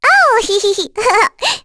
Luna-Vox_Happy2.wav